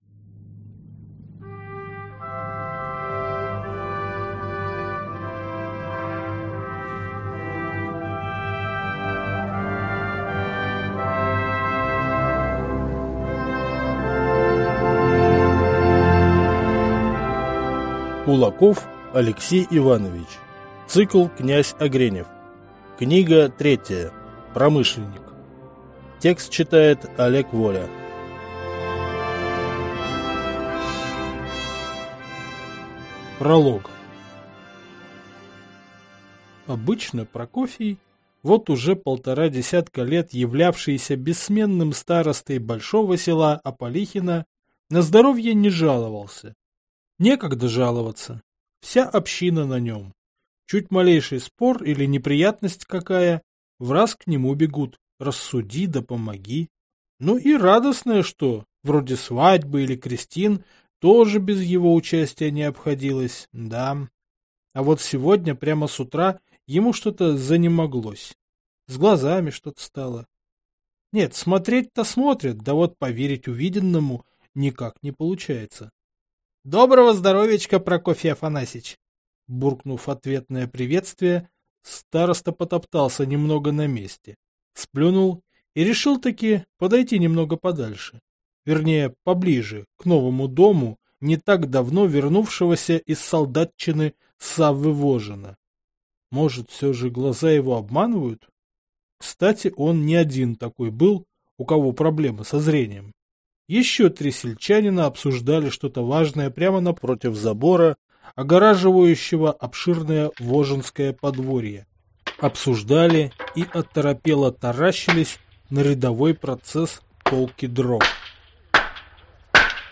Аудиокнига Промышленникъ | Библиотека аудиокниг
Прослушать и бесплатно скачать фрагмент аудиокниги